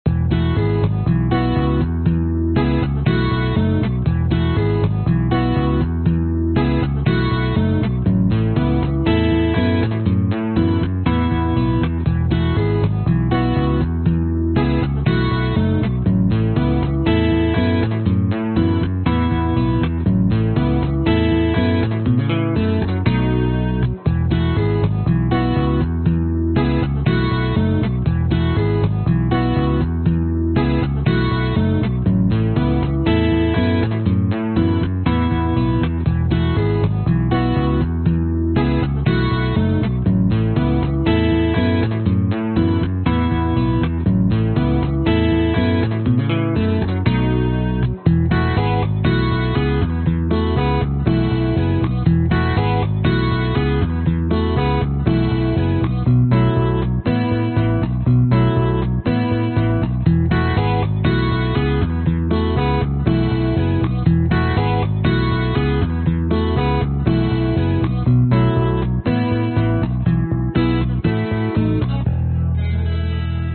描述：原声吉他和贝斯和弦的进展，关键。E
Tag: 吉他 原声 贝斯 民谣 器乐